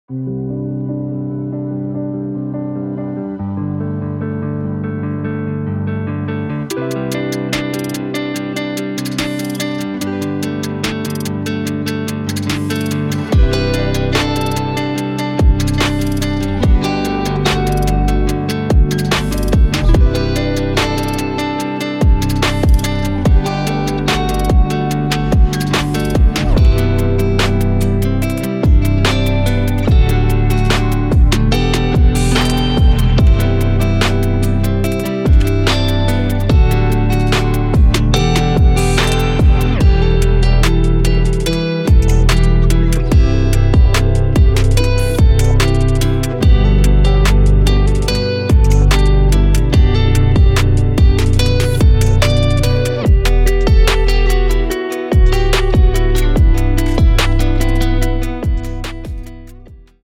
We are proud to present our new guitar collection!
Each melody is carefully worked out and recorded live.
In addition, we included drums, basslines and some synths.